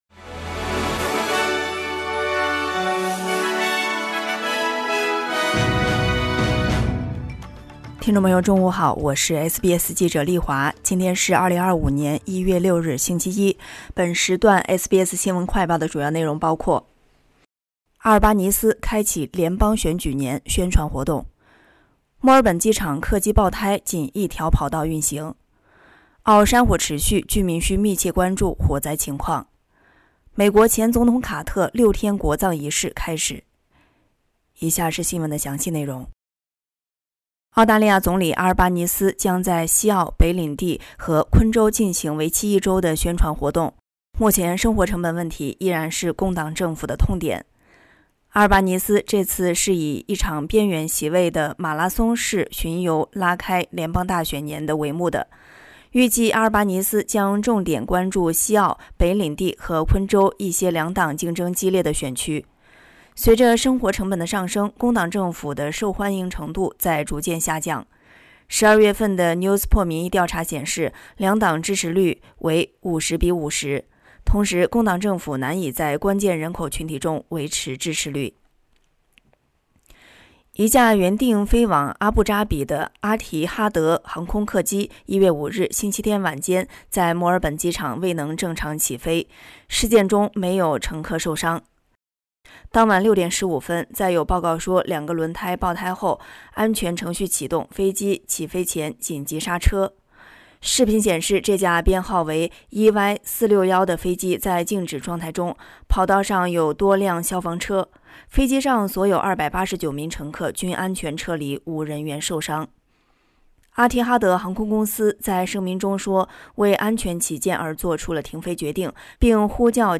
【SBS新闻快报】阿尔巴尼斯开启联邦选举年宣传活动